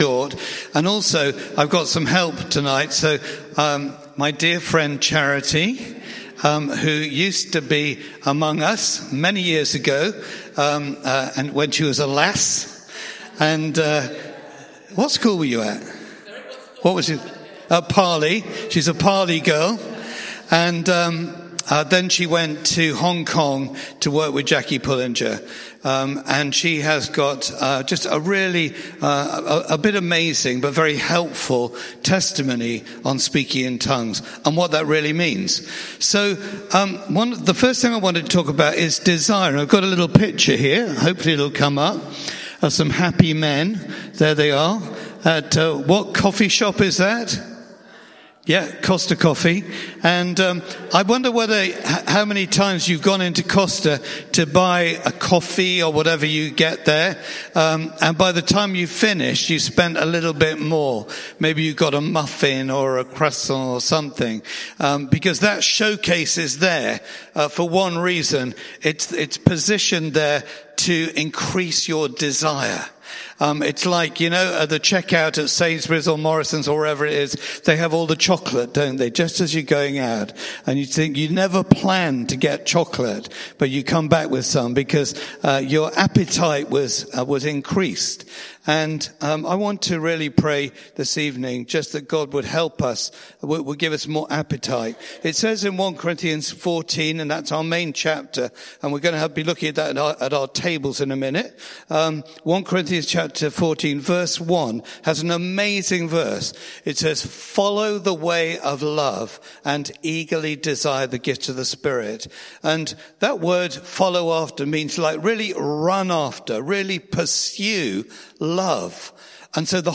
Bassett Street Sermons